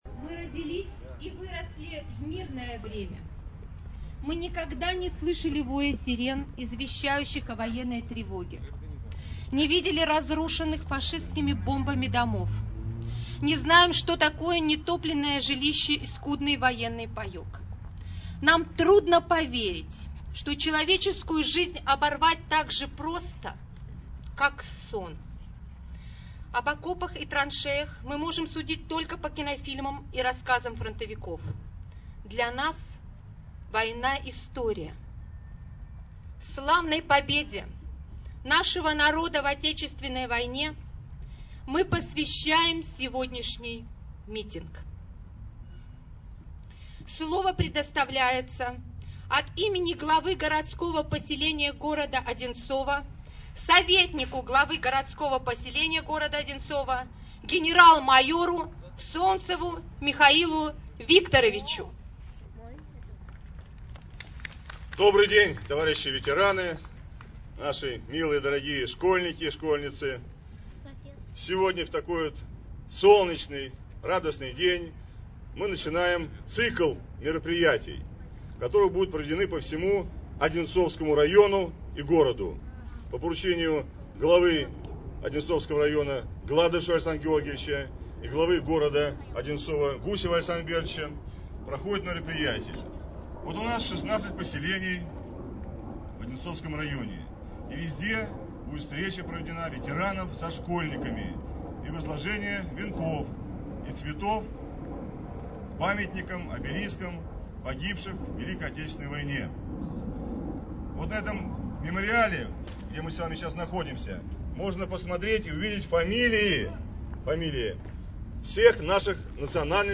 запись с мероприятия, посвященного 66-летию Великой Победы
Также можно послушать не монтированную запись с мероприятия, посвященного 66-летию Великой Победы .